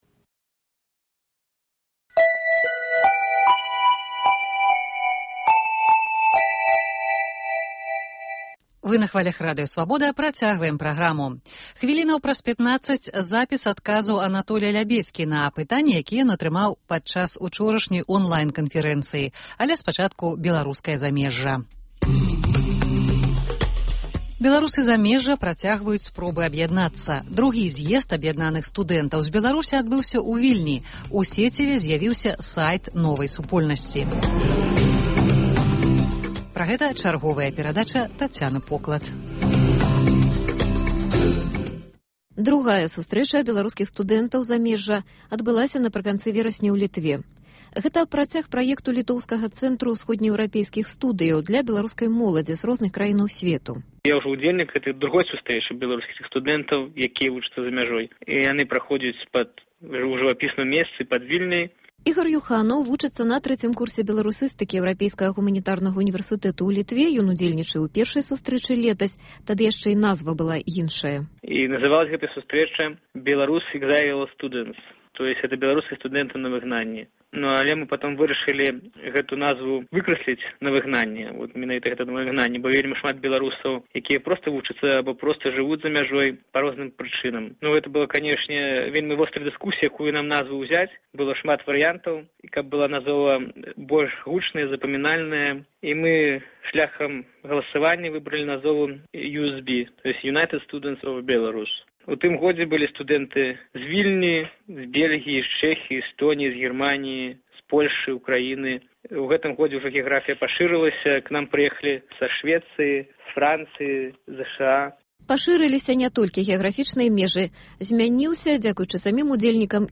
Беларуская дыяспара ў глябалізаваным сьвеце. Размова пра беларускія студэнцкія таварыствы за мяжой.